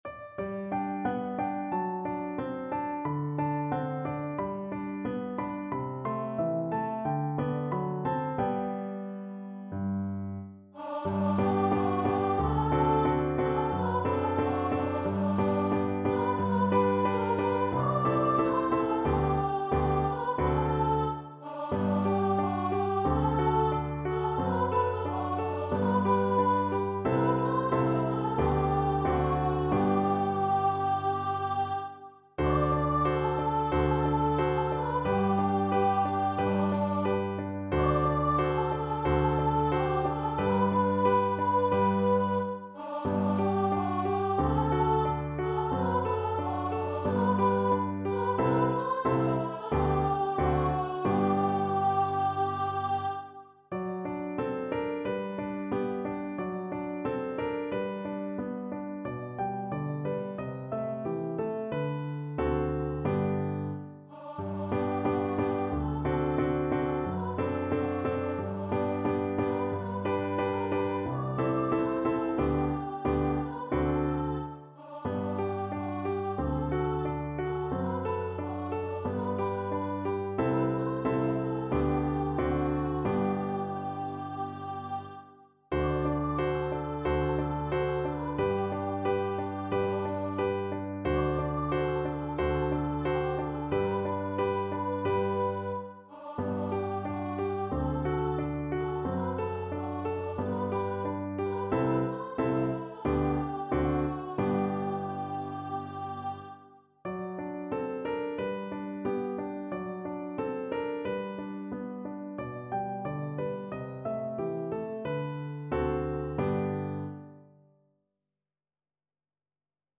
4/4 (View more 4/4 Music)
D5-D6
Andante, con espressione =90